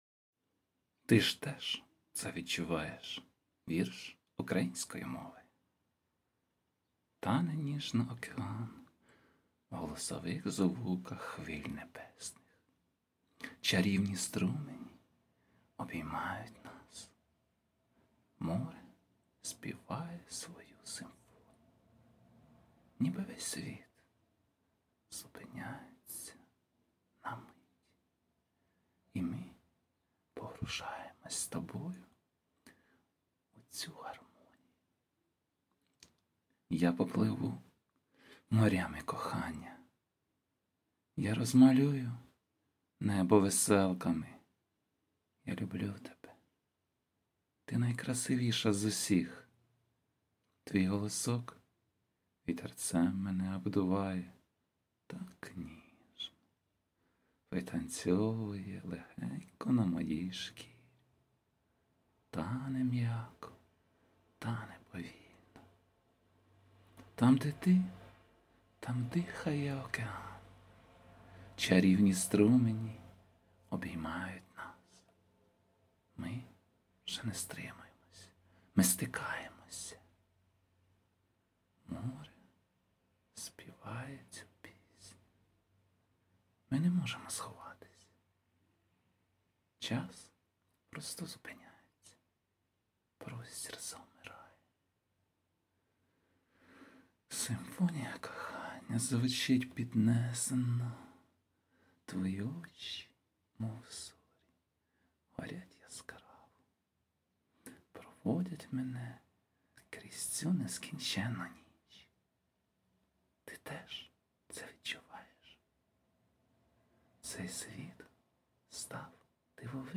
дякую, добавив звуковий супровід hi